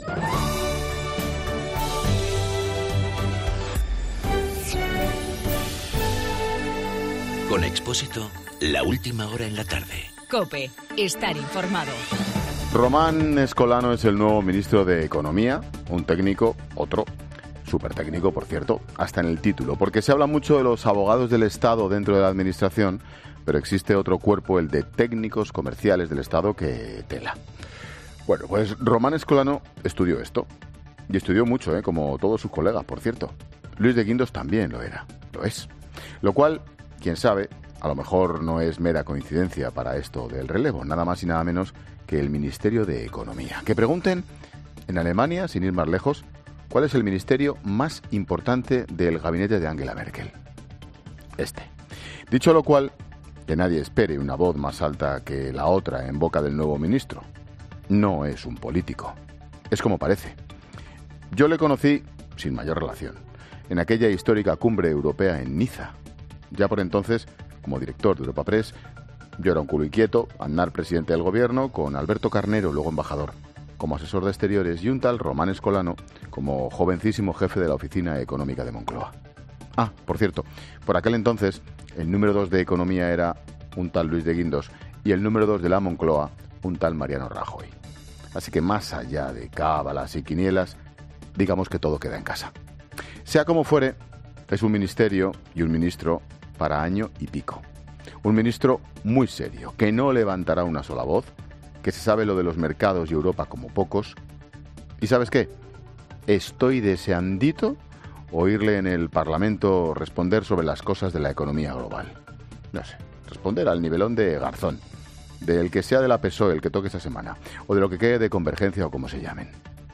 AUDIO: El comentario de Ángel Expósito sobre el nuevo ministro de Economía.